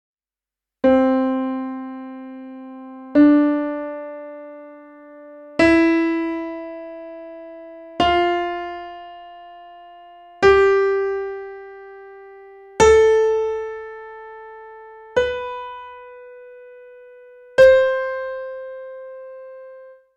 C Major
Ex-1-C-Major.mp3